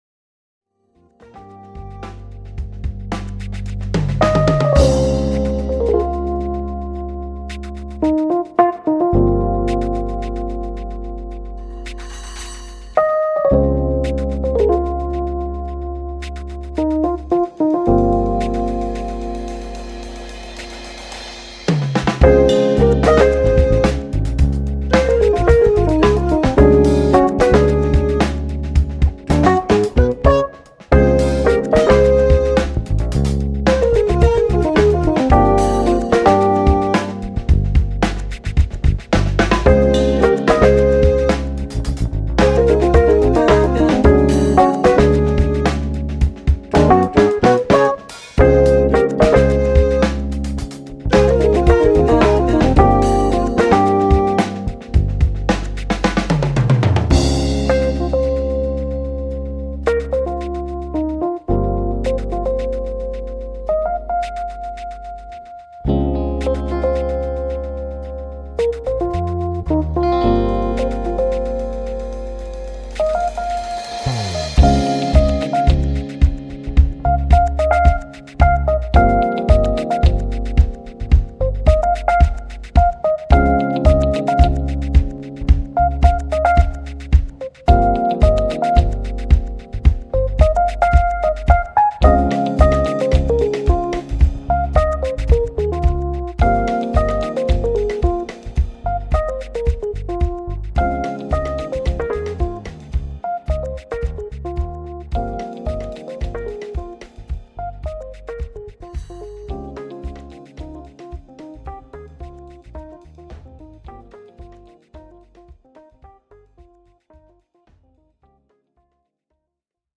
Aufgenommen im April 2006 in Augsburg.
Bass, Samples, Programming
Gitarre
Keyboards
Drums, Samples